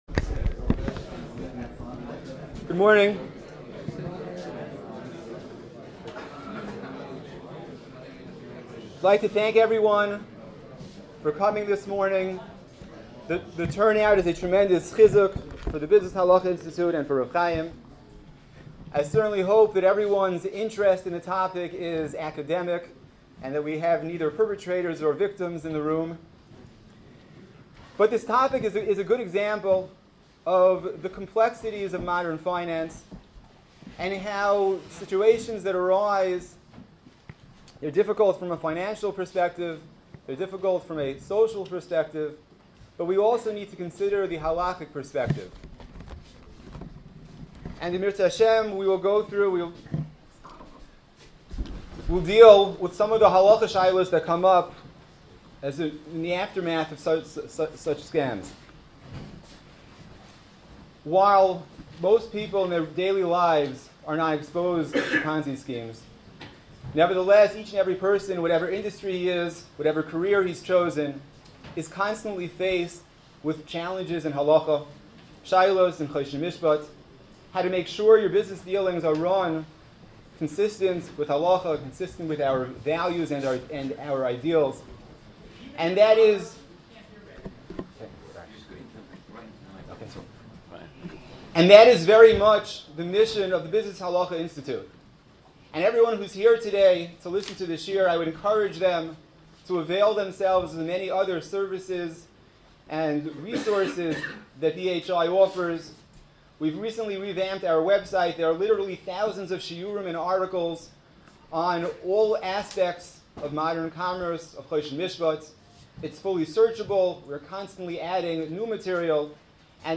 Ponzi Schemes and Other Scams Panel Discussion- BHI Annual Brunch 2015 | Business Halacha
A Panel Discussion